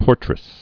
(pôrtrĭs) also por·ter·ess (-tər-ĭs)